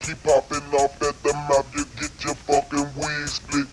Wig Split - Dj Sound.wav